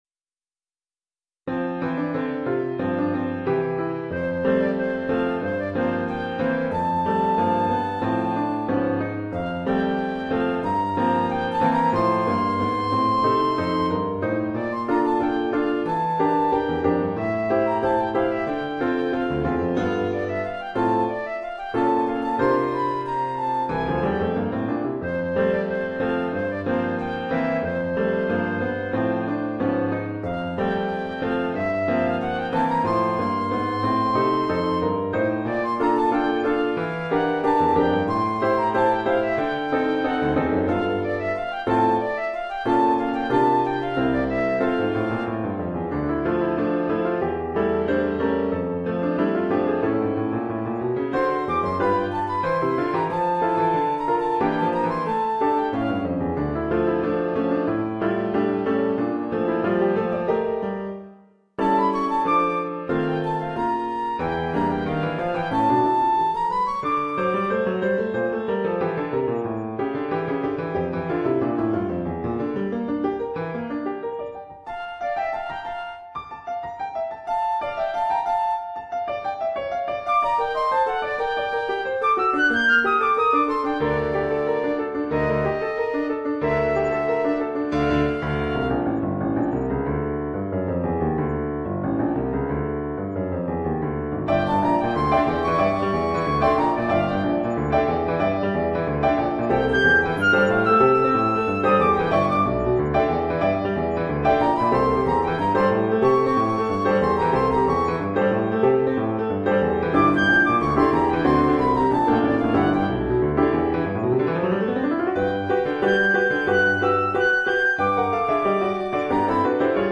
Flûte traversière
Oeuvre pour flûte et piano.